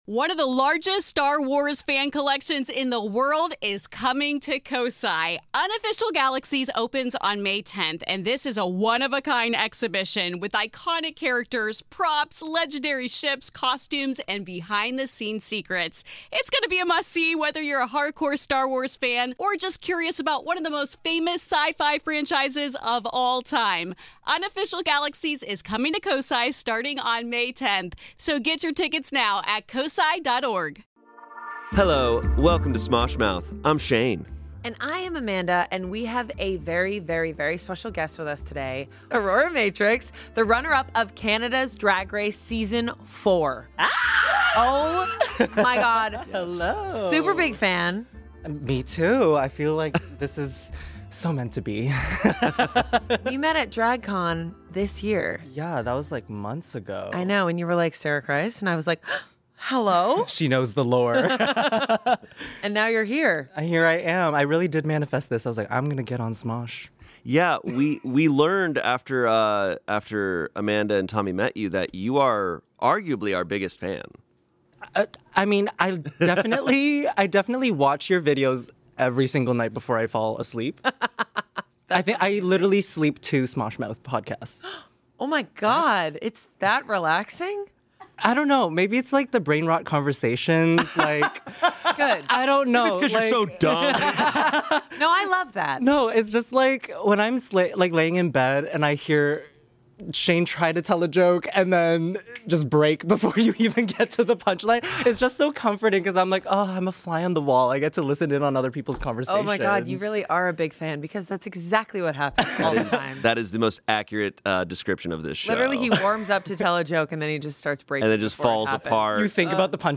Amanda and Shayne are joined by the lovely Aurora Matrix, drag queen extraordinaire and Smosh Mouth's biggest fan!